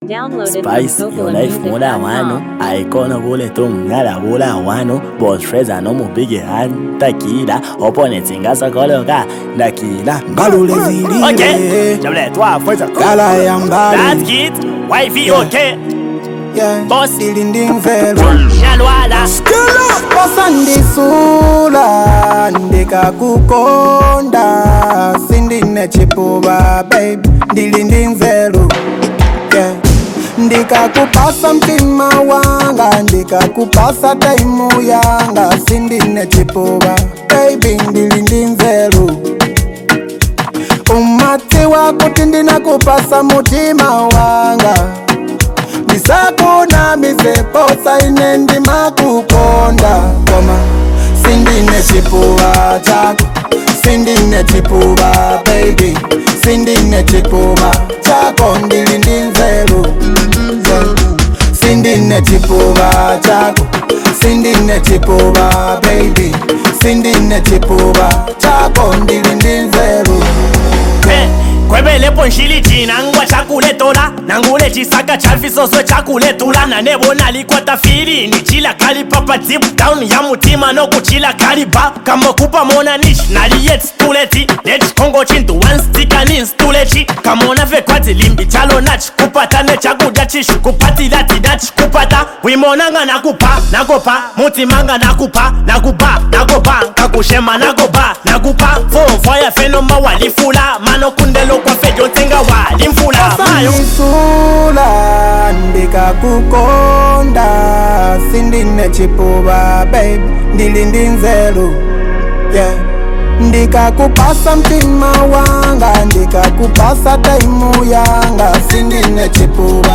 Zambian Music
a reflective and emotionally grounded song